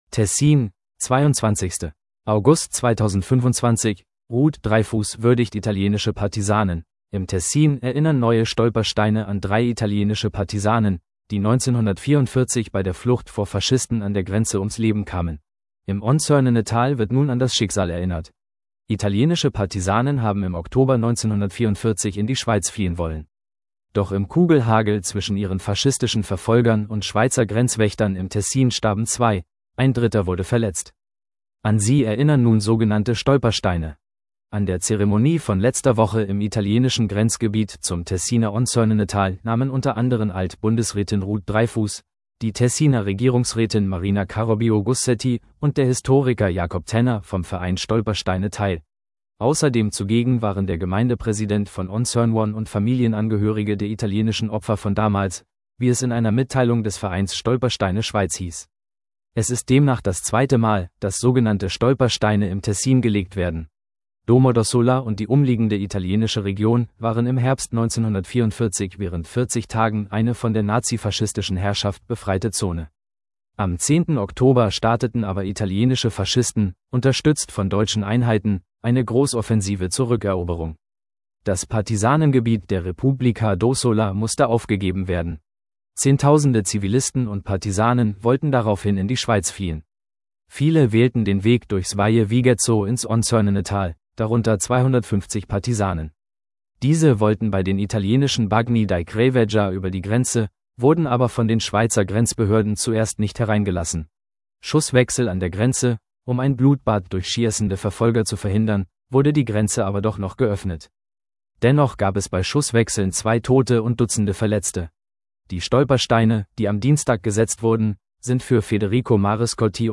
Alt-Bundesrätin Ruth Dreifuss bei ihrer Rede im Tessin.